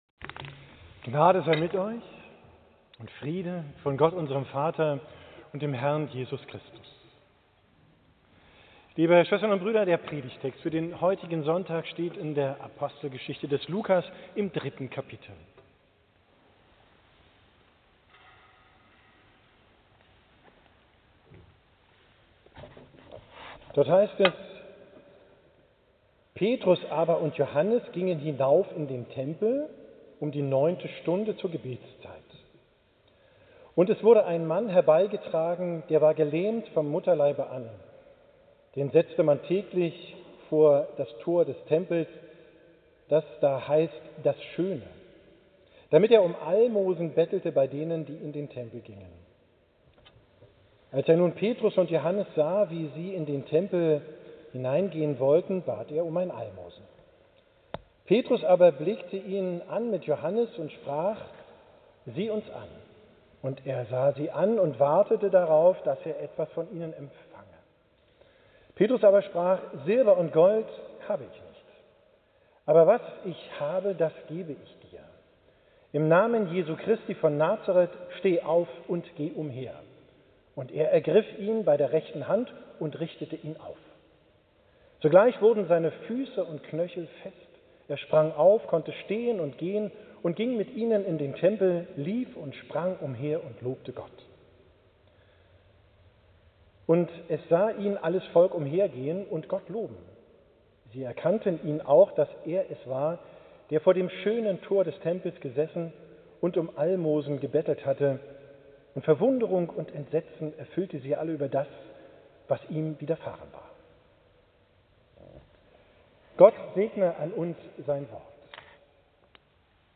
Predigt vom 12. Sonntag nach Trinitatis, 7. IX 2025